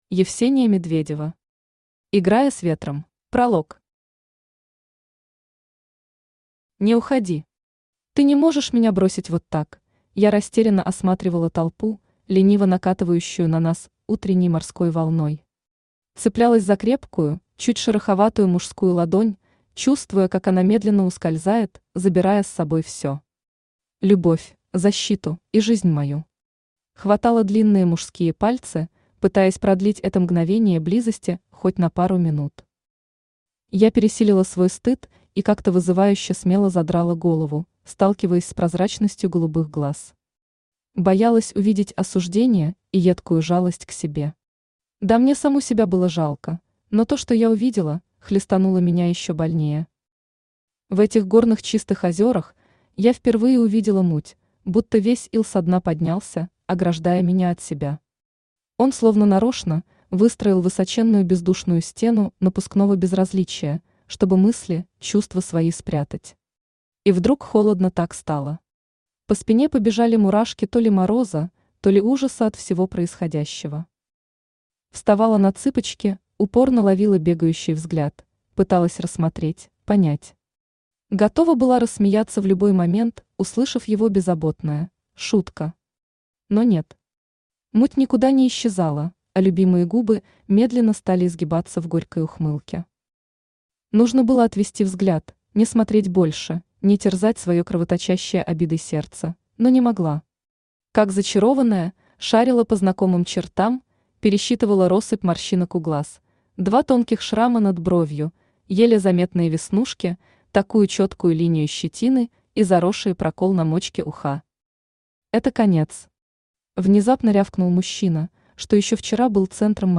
Аудиокнига Играя с ветром | Библиотека аудиокниг